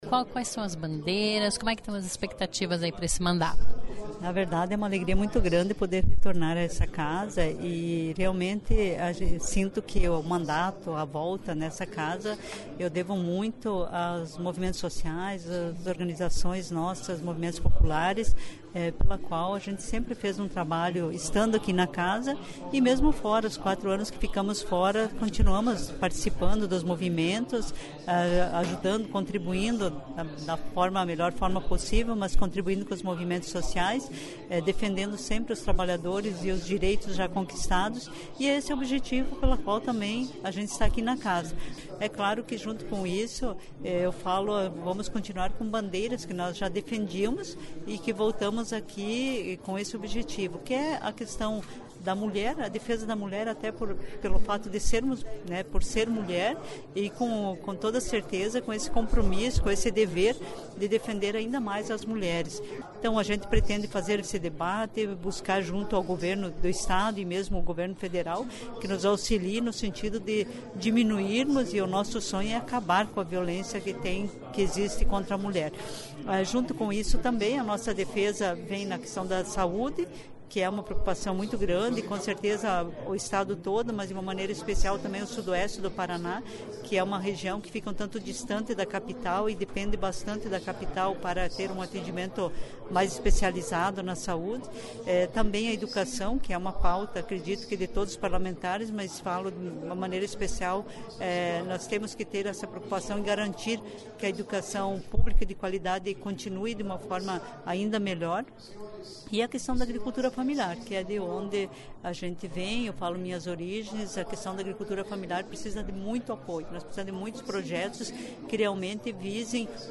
Ouça a entrevista da deputada do PT, que volta à Casa para mais um mandato.